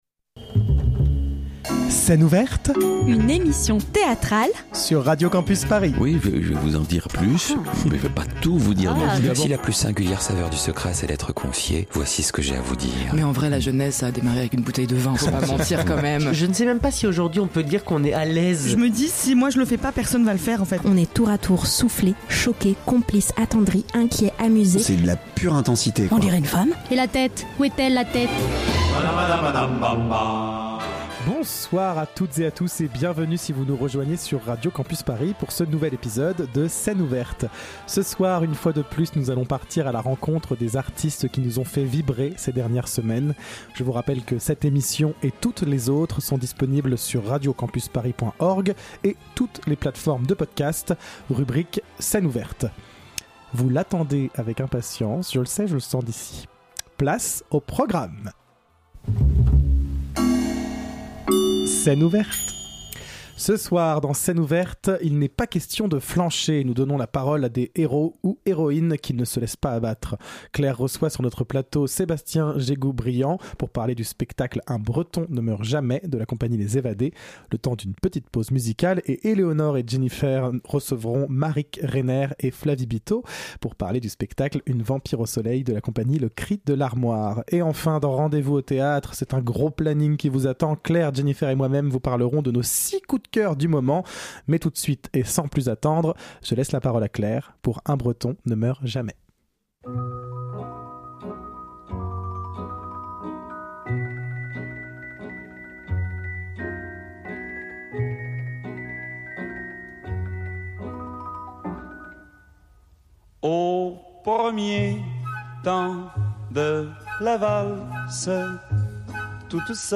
Type Magazine Culture